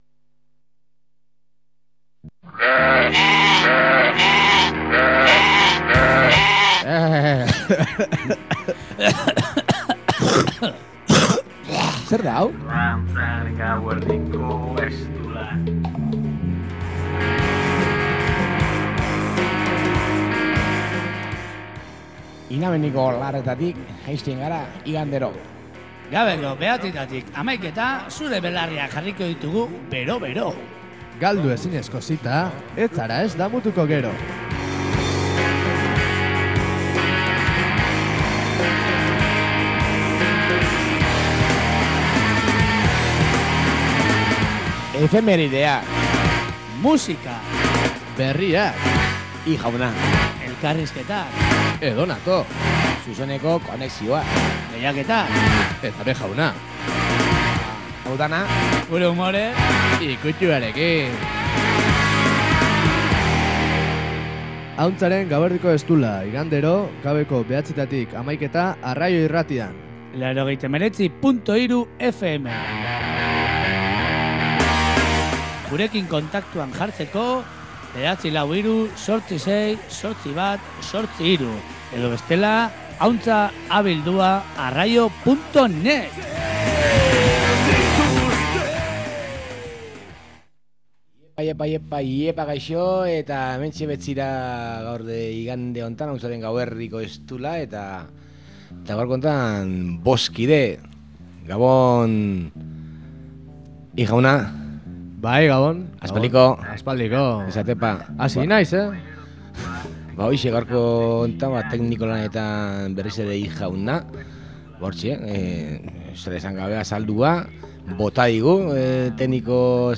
Atzokoan hainbat alderdi politiko minoritarioekin aritu ginen elkarrizketan. PACMA, Por un mundo mas Justo, Partido humanista, EQUO eta POSI alderdiko kideekin izan ginen, hain zuzen ere.